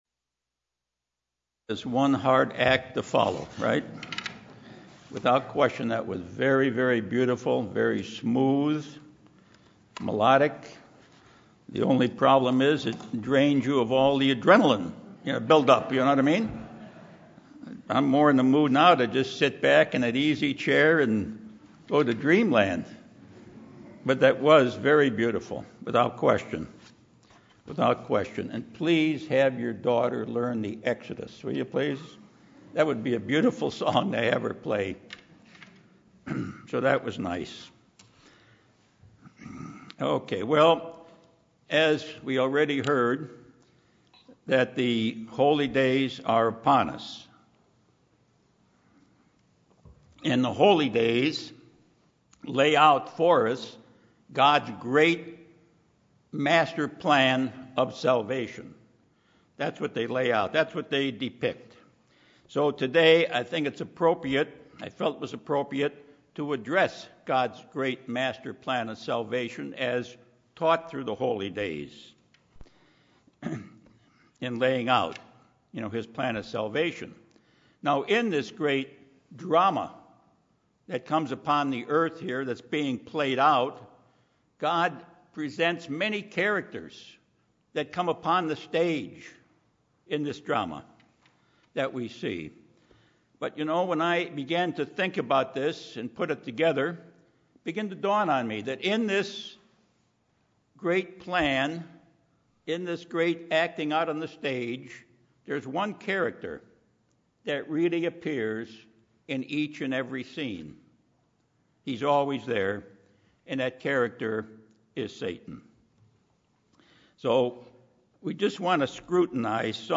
UCG Sermon Studying the bible?
Given in Los Angeles, CA